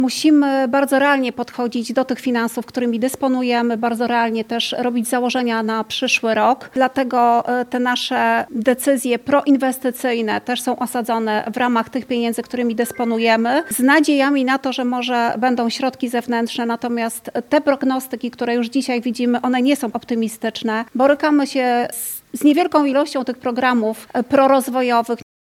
Wicestarosta Anna Gawrych zwróciła uwagę, że Powiat Łomżyński nie może obecnie liczyć na środki rządowe skierowane na podobne inwestycje.